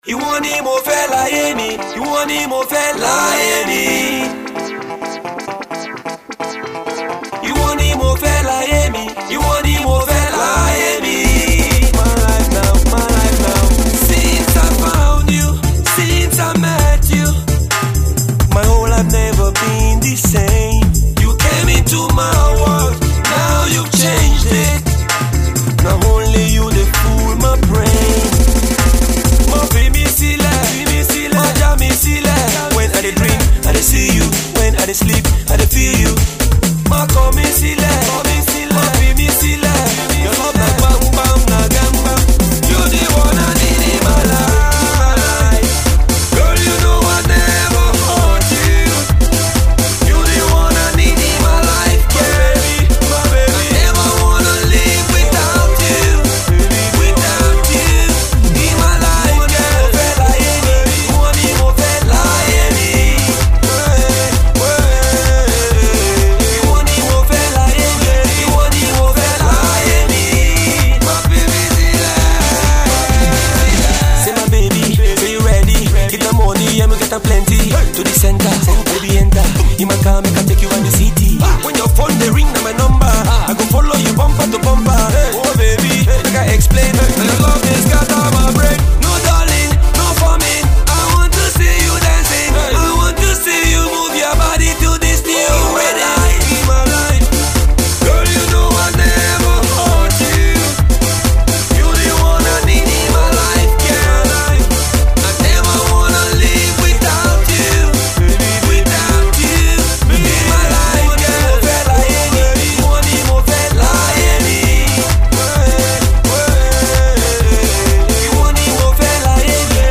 is more of the uptempo, move-your-body track